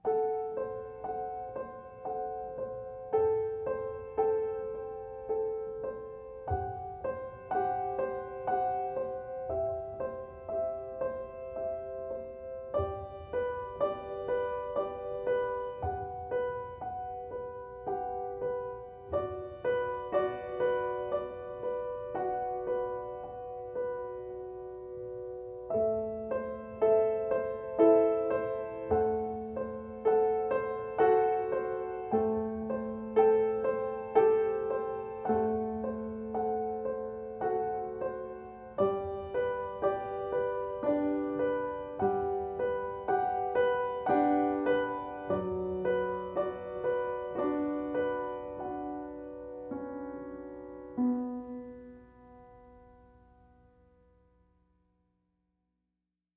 piano, violin, cello, vocals